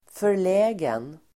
Ladda ner uttalet
förlägen adjektiv, awkward , embarrassed , shy Uttal: [för_l'ä:gen] Böjningar: förläget, förlägna Synonymer: blyg, generad Definition: generad, blyg embarrassed adjektiv, generad , förlägen , besvärad , pinsamt